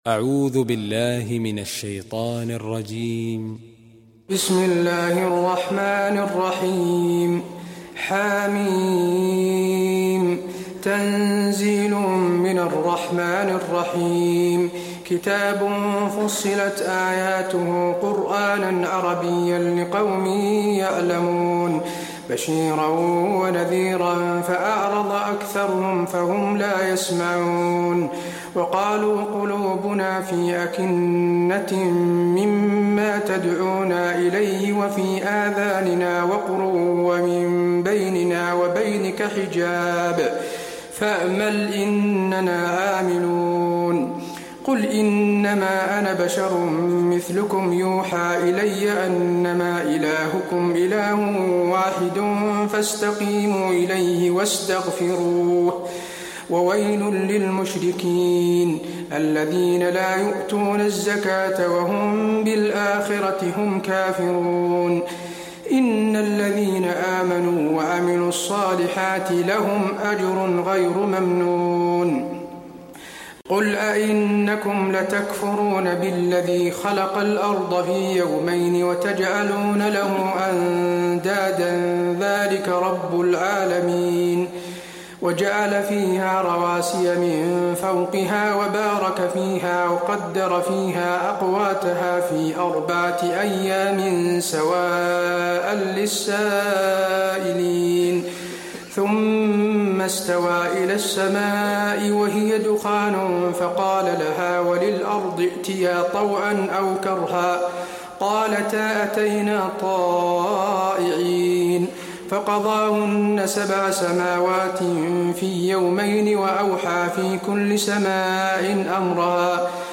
المكان: المسجد النبوي فصلت